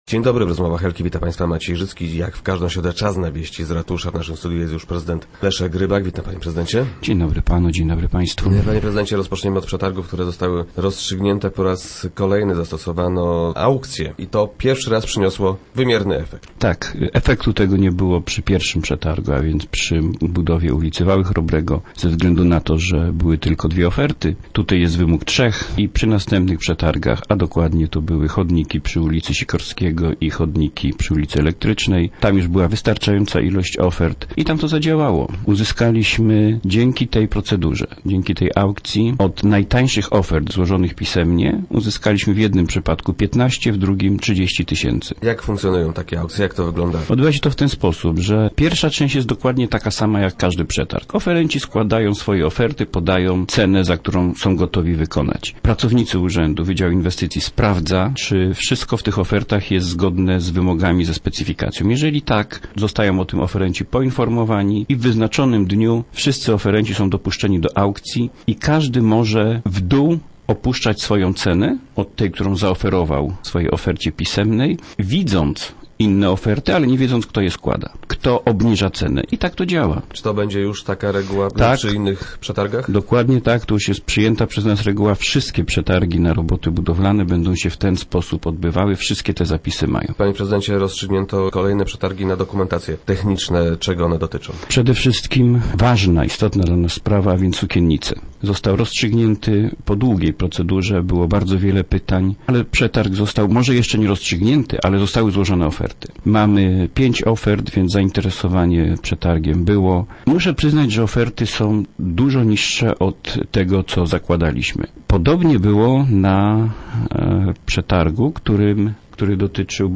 Organizowane są także internetowe aukcje. - Zaczynają one już przynosić oszczędności - twierdzi Leszek Rybak, zastępca prezydenta Głogowa, który był dziś gościem Rozmów Elki.